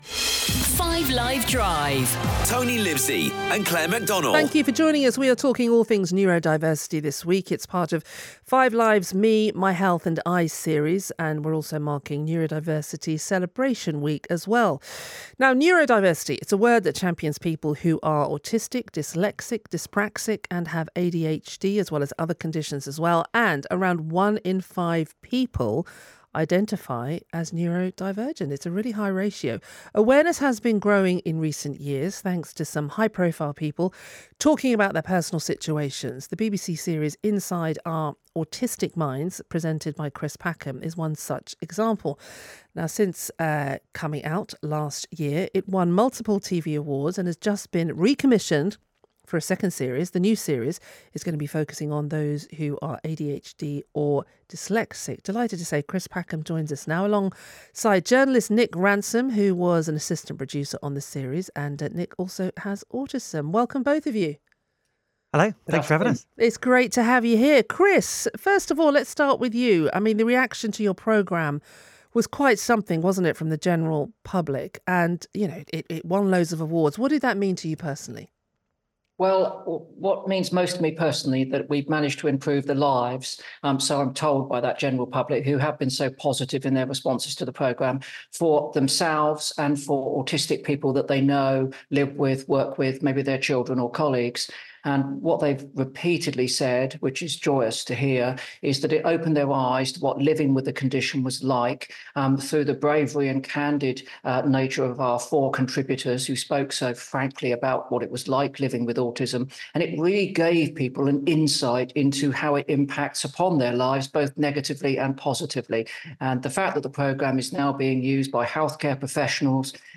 With the week gaining momentum, I joined naturalist, presenter and neurodiversity advocate Chris Packham on 5 Live Drive to discuss the success of Inside Our Autistic Minds and the new series.
Two days later on the same programme, I reported live from London and Neurodiverse Sport's inaugural event, encouraging greater understanding in the industry.